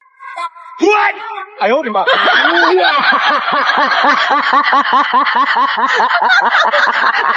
Smoke Sound Effects MP3 Download Free - Quick Sounds